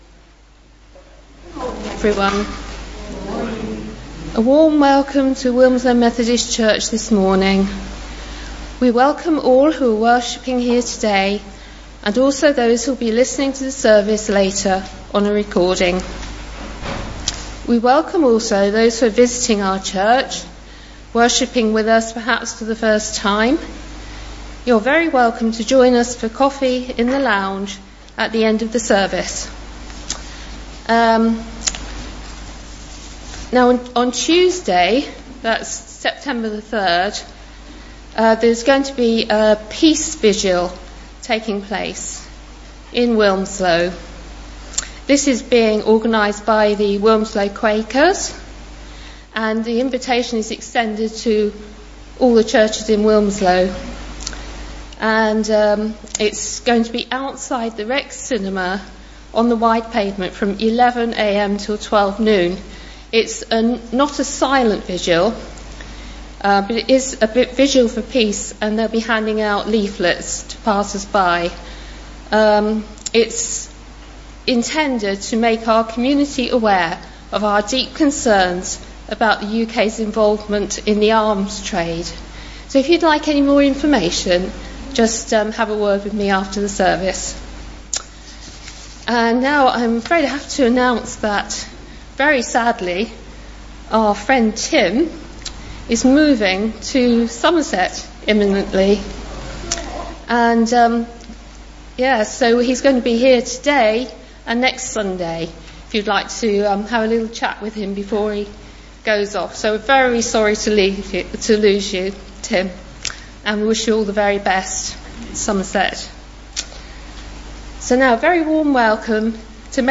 2019-09-01 Morning service
Genre: Speech.